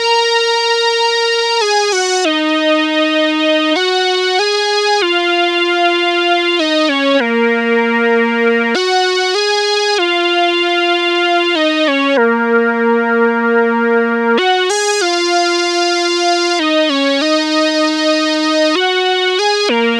Night Rider - Analog Lead.wav